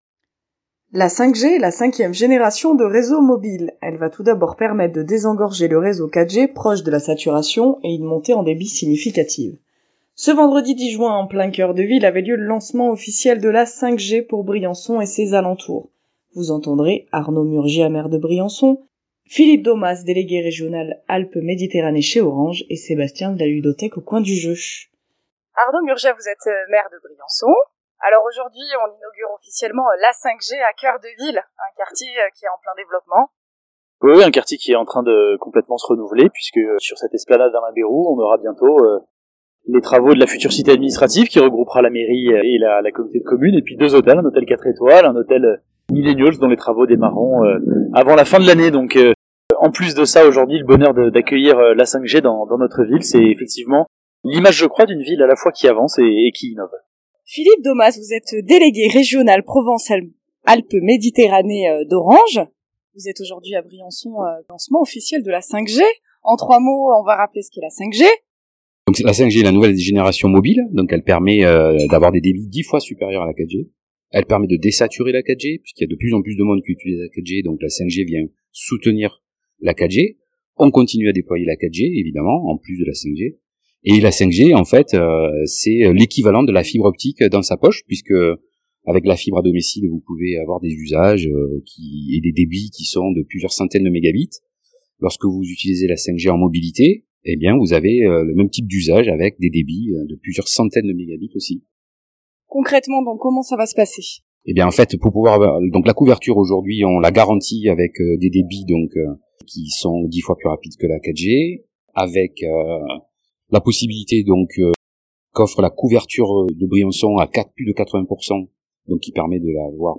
Elle va tout d'abord permettre de désengorger le réseau 4G, proche de la saturation, et une montée en débit significative. Ce vendredi 10 juin en plein coeur de ville, avait lieu le lancement officiel de la 5G pour Briançon et ses alentours.